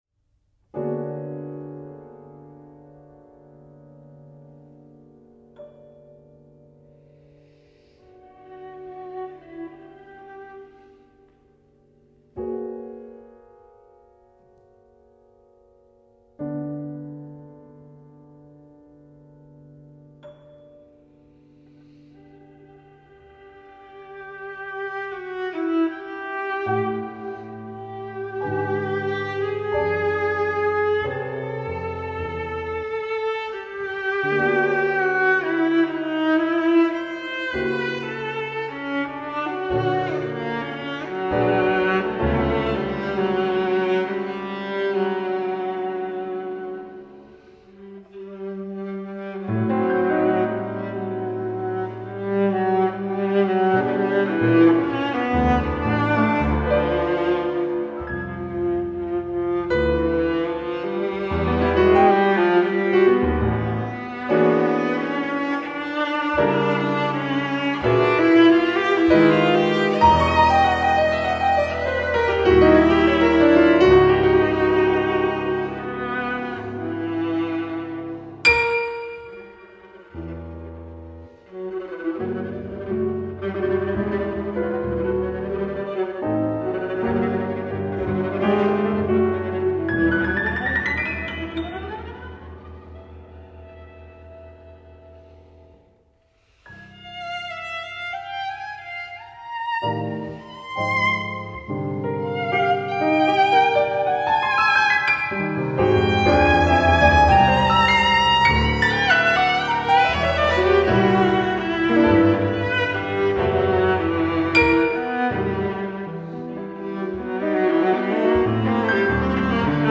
for viola and piano